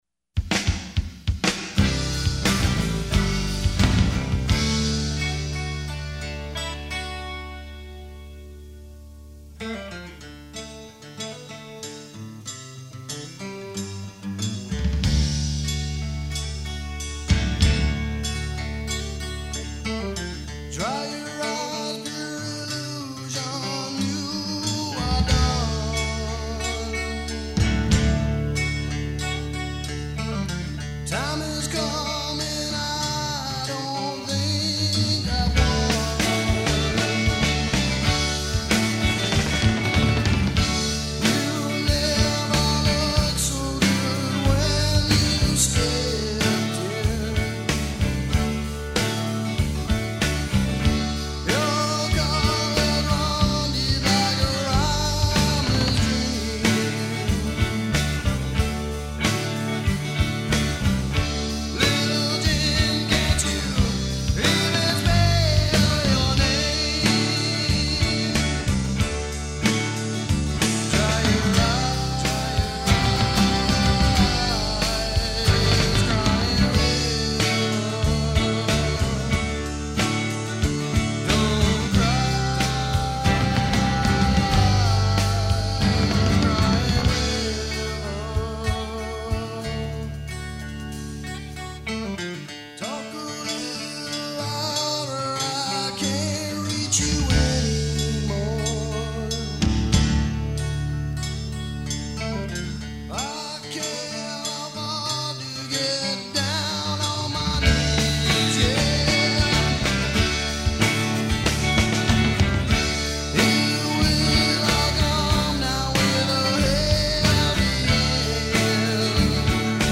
Vocals
Guitar
Drums
Bass